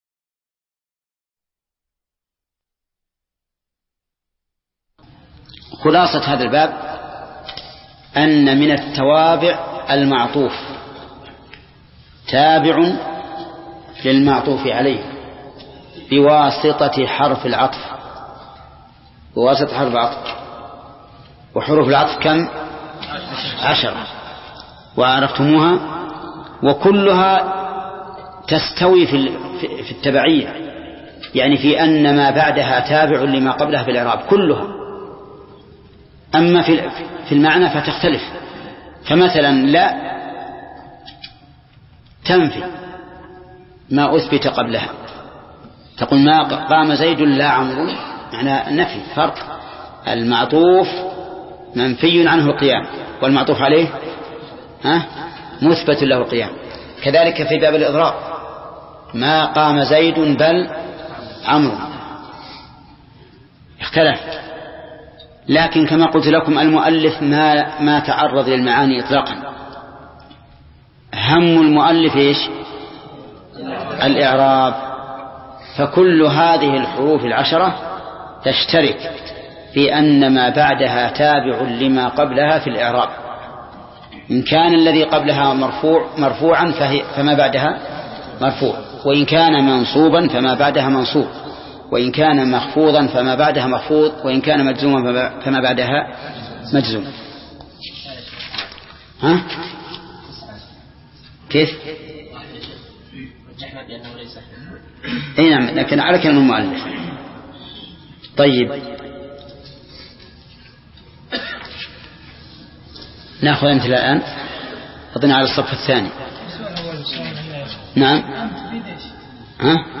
درس (16) : شرح الآجرومية : من صفحة: (328)، قوله: (والخلاصة).، إلى صفحة: (347)، قوله: (البدل).